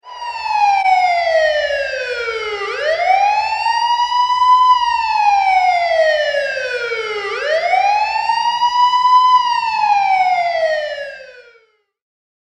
Sirene da Polícia Militar
Categoria: Sons de sinos e apitos
sirene-da-policia-militar-pt-www_tiengdong_com.mp3